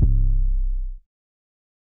808 (YOSEMITE).wav